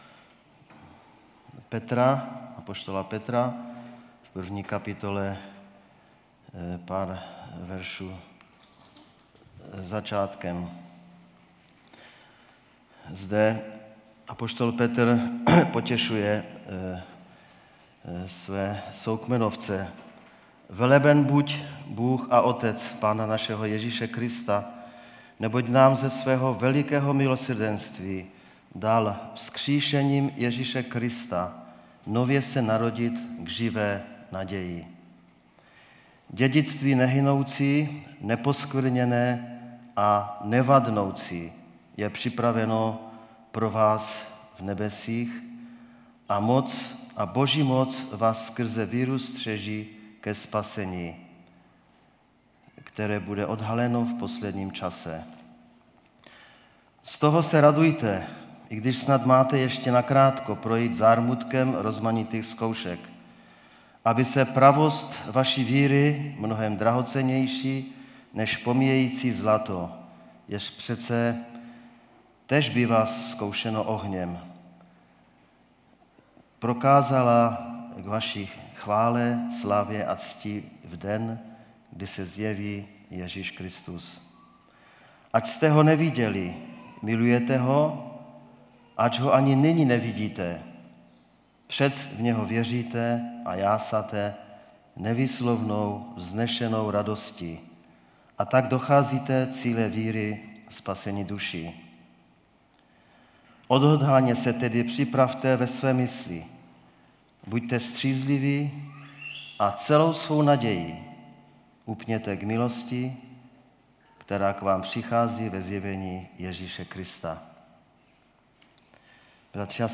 Nedělní bohoslužby